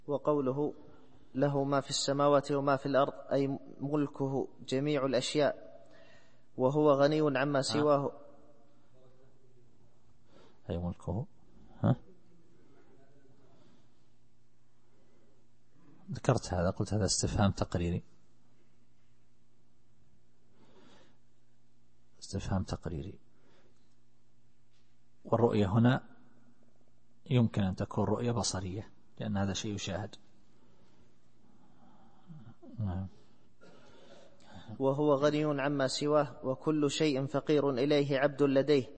التفسير الصوتي [الحج / 64]